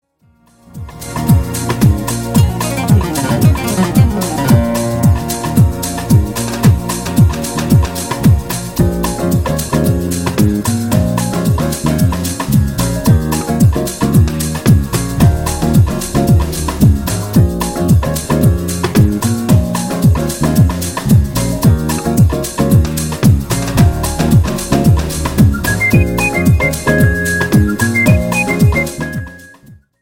JAZZ  (02.23)